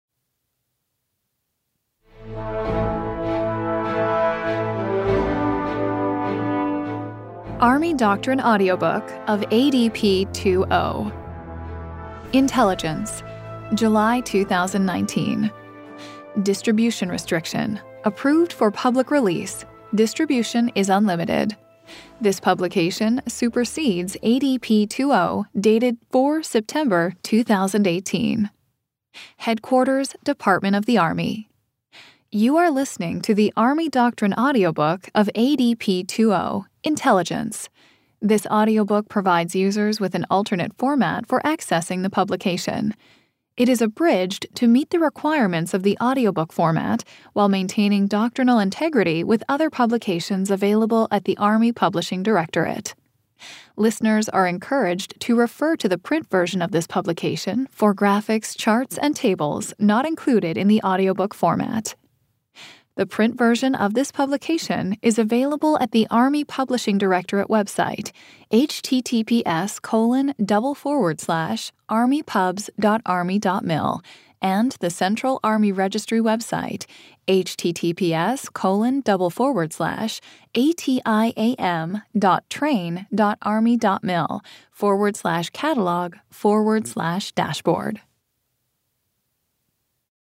This is the download page for the Frontmatter of the Army Doctrine Audiobook of Army Doctrine Publication (ADP) 2-0, Intelligence. ADP 2-0 is the Army’s most fundamental publication for Army intelligence.
It has been abridged to meet the requirements of the audiobook format.